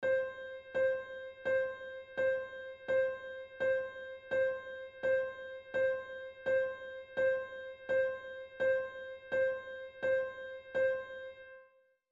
which tempo-wise speeds along in minims (half-notes) like this:
metro.mp3